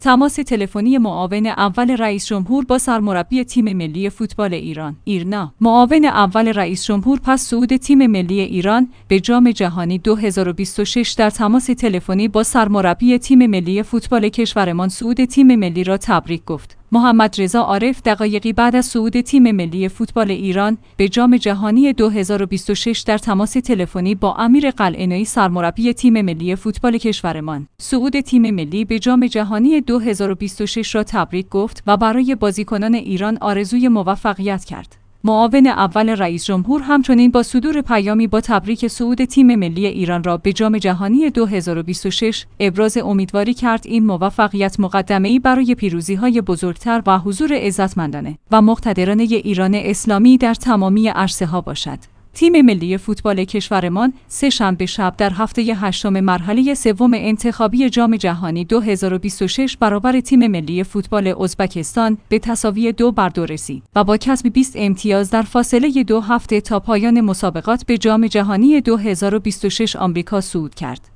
تماس تلفنی معاون اول رئیس‌جمهور با سرمربی تیم ملی فوتبال ایران